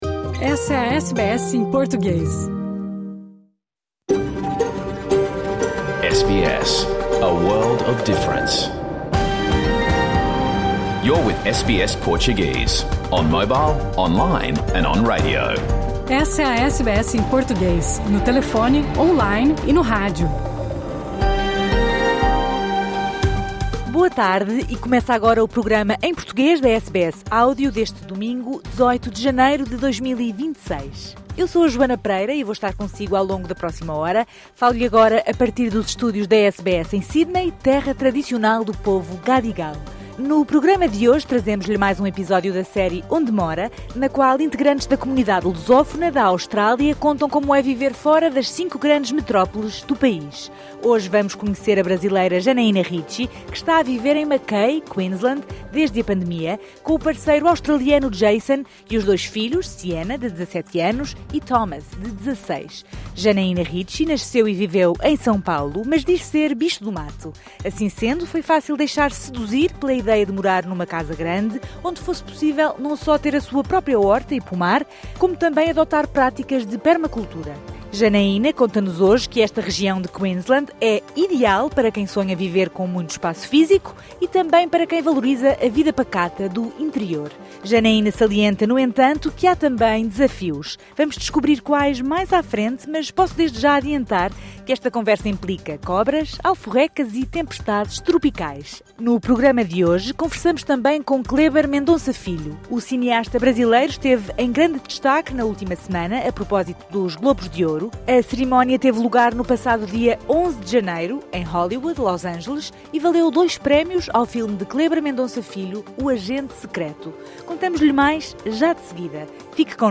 Programa ao vivo | Domingo 18 de janeiro